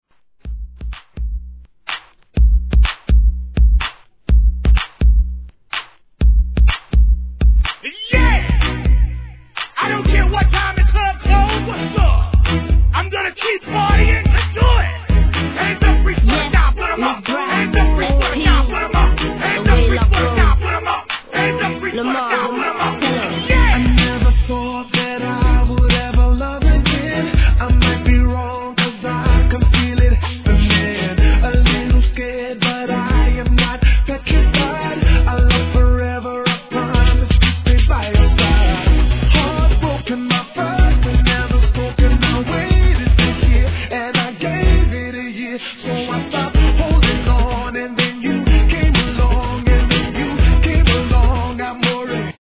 HIP HOP/R&B
[BPM124]  A2.